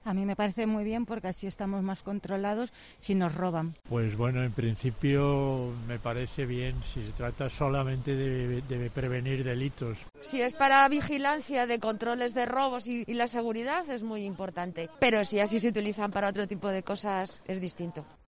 En COPE, nos hemos acercado hasta la Puerta del Sol, para saber qué opinan los vecinos, la gente que pasea, y los negocios, sobre el nuevo plan del Ayuntamiento de Madrid.